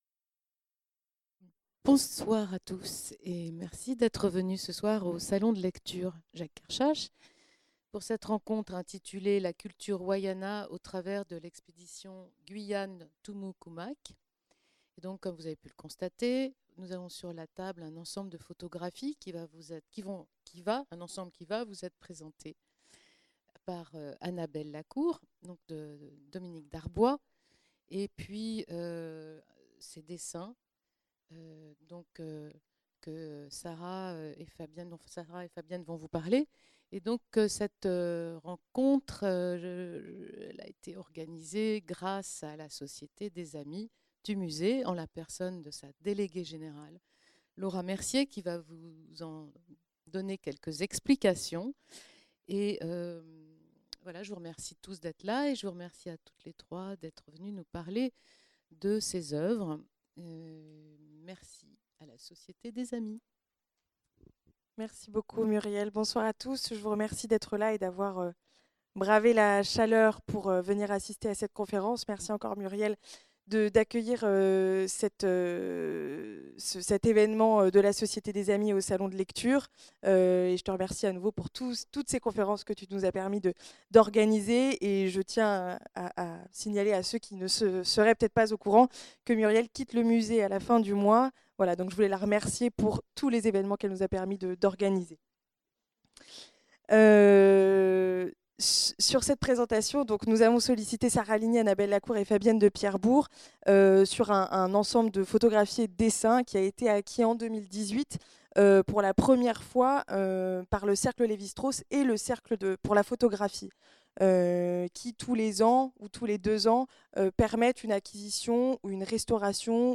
Rencontre et sortie d’objets en collaboration avec la Société des Amis du musée du quai Branly-Jacques Chirac.